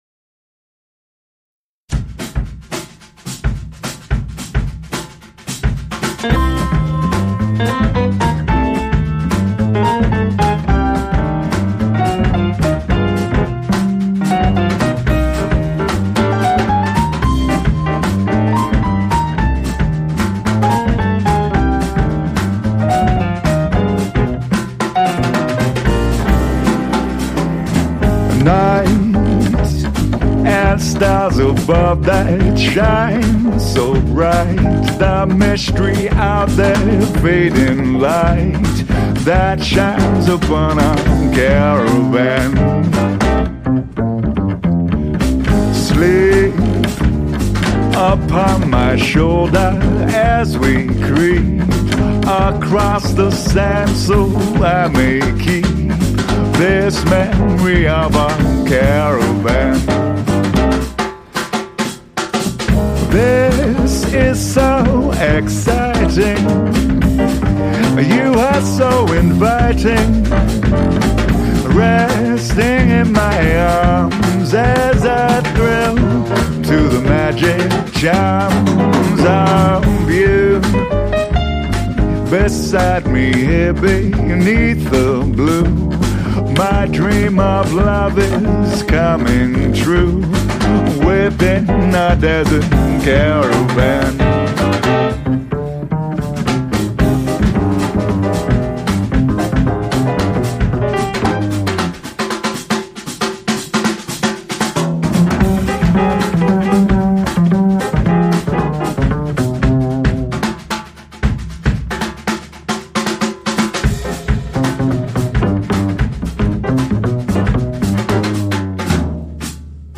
Vocals/Piano, Double Bass, Drums